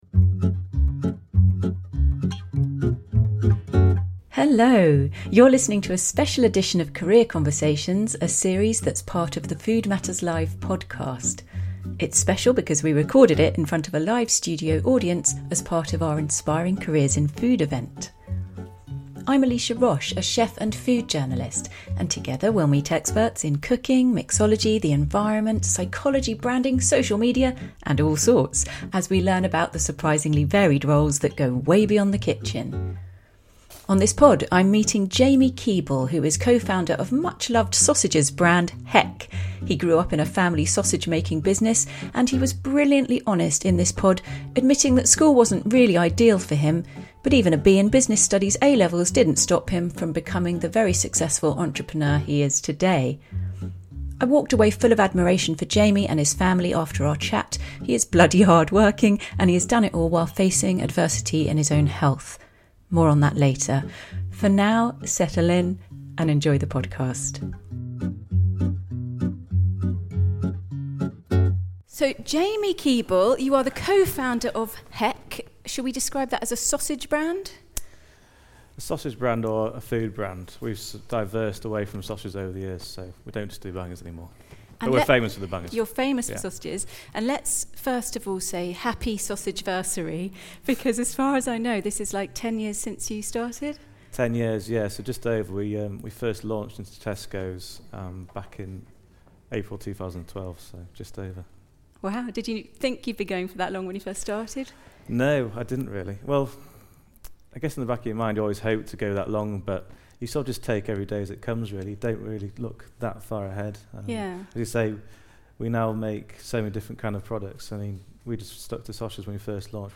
recorded in front of a live audience as part of our Inspiring Careers in Food events